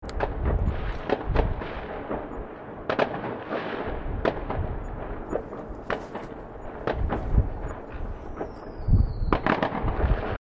烟花在城市3
描述：新的一年烟花在城市的声音